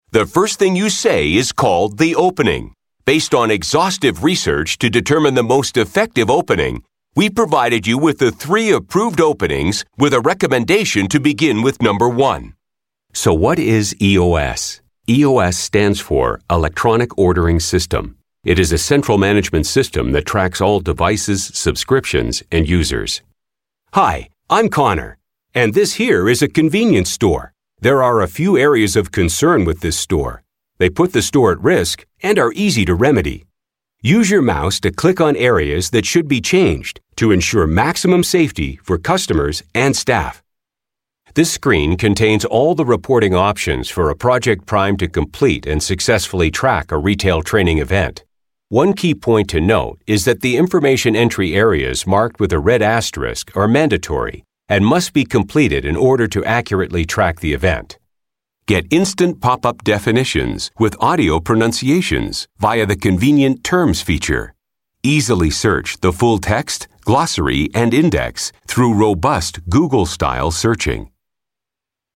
English (Canadian)
E-learning
Sennheiser MKH 416 microphone
Custom built voice booth
BaritoneBassDeep
ConfidentGroundedSeriousAuthoritativeConversationalCorporateExperienced